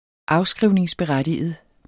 Udtale [ ˈɑwˌsgʁiwˀneŋs- ]